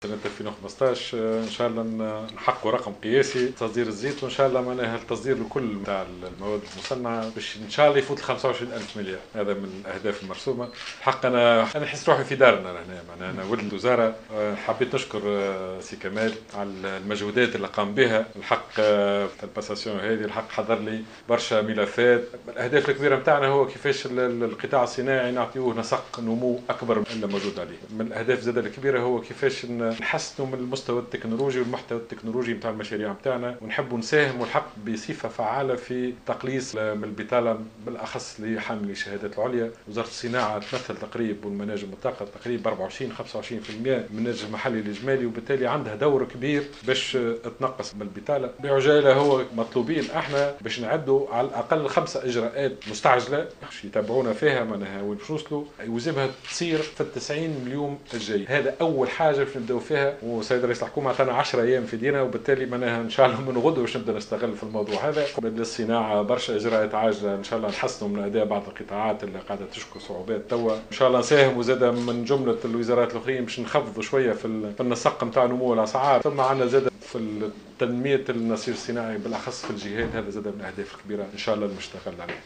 توقّع وزير الصناعة والطاقة والمناجم الجديد زكريا أحمد في كلمته خلال موكب تسلم مقاليد الوزارة ارتفاع قيمة الصادرات التونسية إلى 25 مليار دينار خلال 2015 .